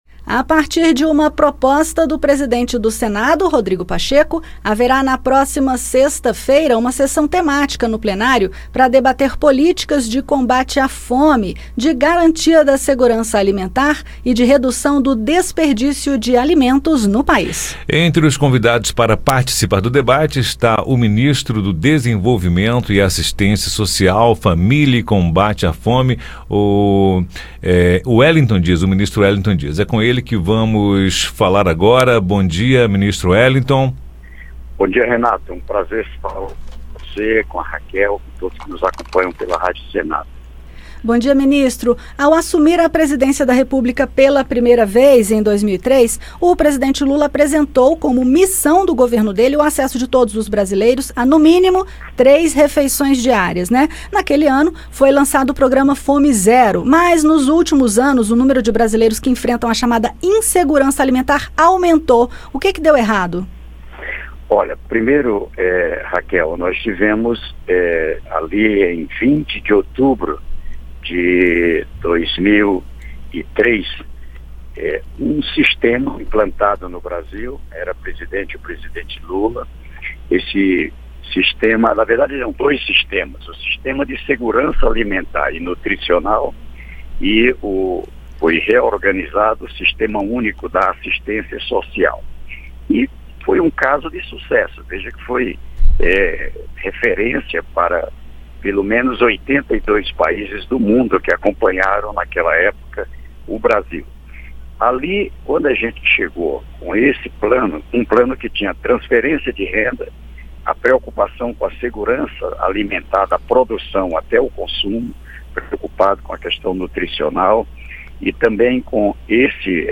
Em entrevista ao Conexão Senado, o ministro fala sobre o Programa Fome Zero, a insegurança alimentar e as ações em andamento para o combate à fome.